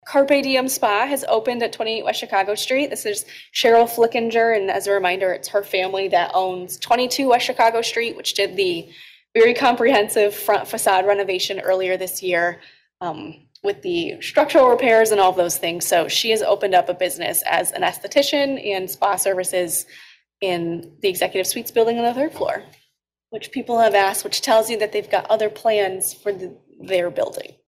during Wednesday’s Downtown Development Authority meeting